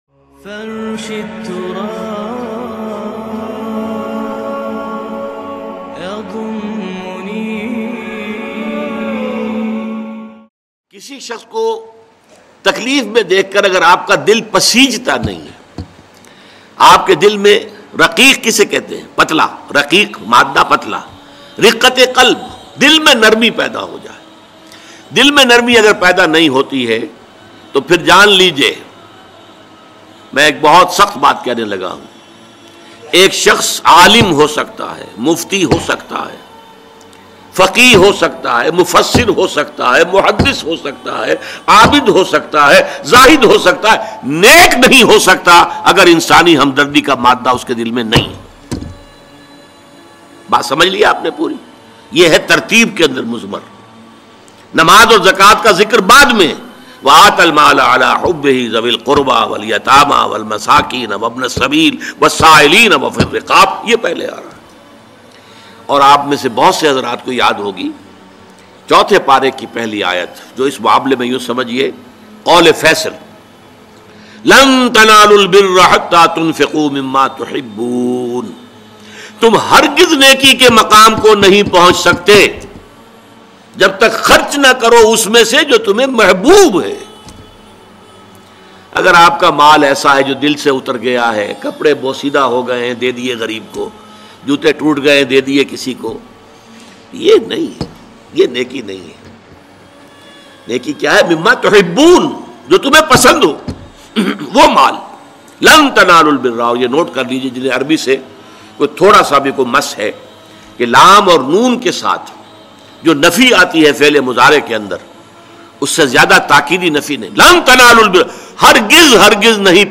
Sadqa Aur Khairat Ki Ahmiyat By Dr Israr Ahmed Very Emotional Bayan MP3 Download.